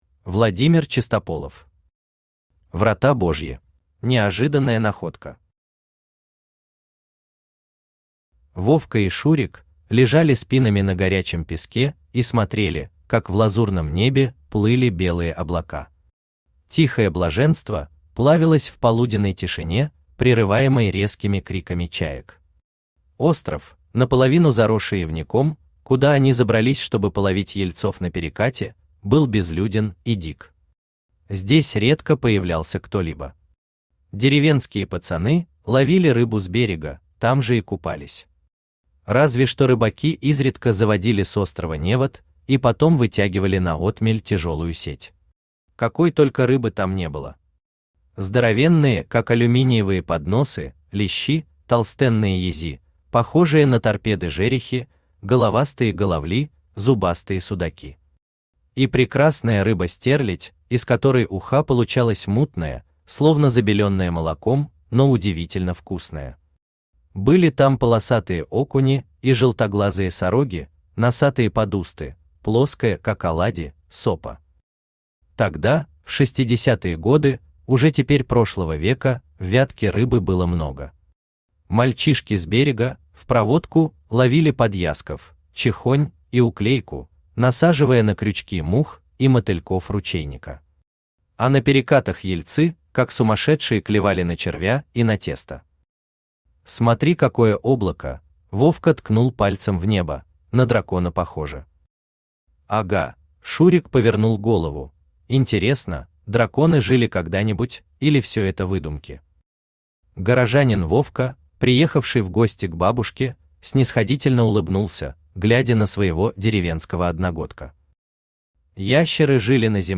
Аудиокнига Врата Божьи | Библиотека аудиокниг
Aудиокнига Врата Божьи Автор Владимир Иванович Чистополов Читает аудиокнигу Авточтец ЛитРес.